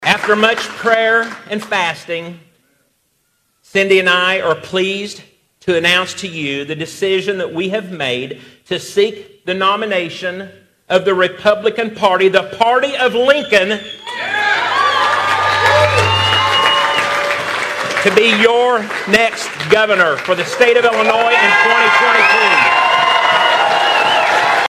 Bailey made the announcement Monday alongside family and friends at the Thelma Keller Convention Center in Effingham.